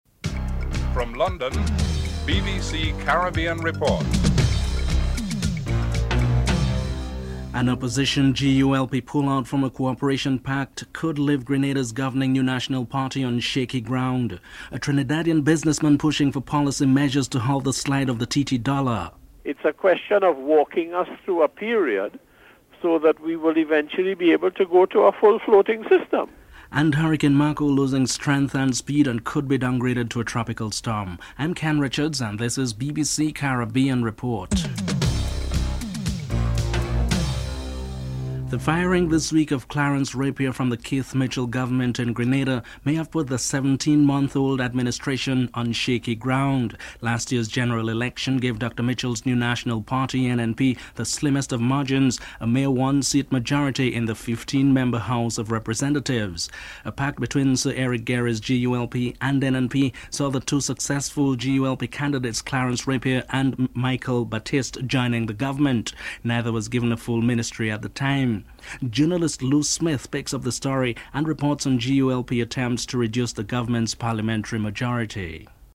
Prime Minister Manuel Esquivel is interviewed (10:46-12:19)